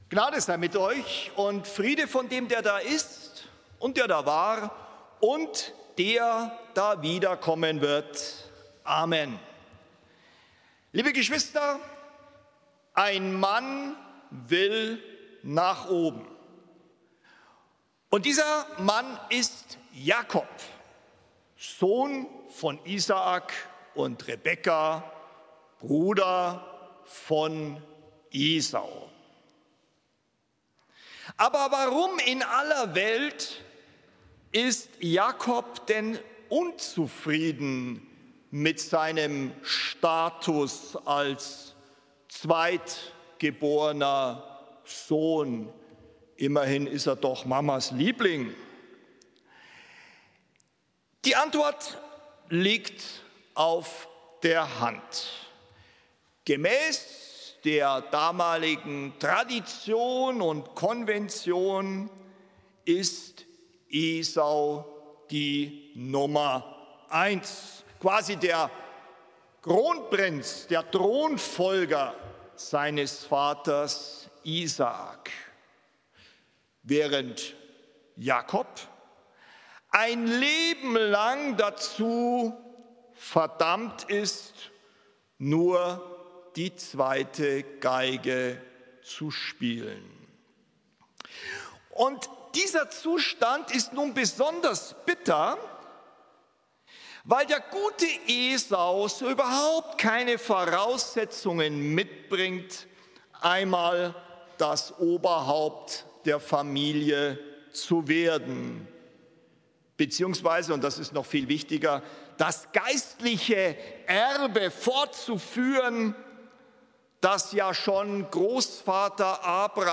Download mp3: Predigt vom 10.12.2023 Spätgottesdienst